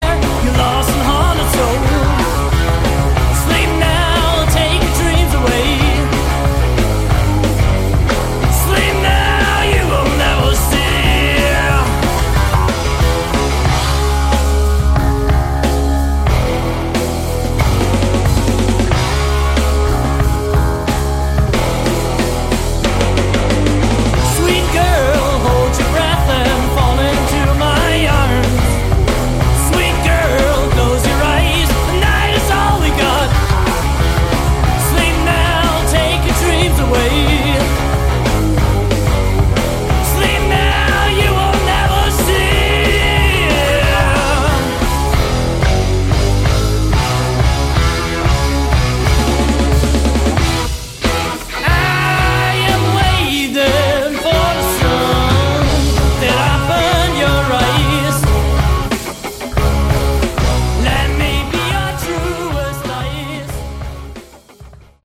Category: Classic Hard Rock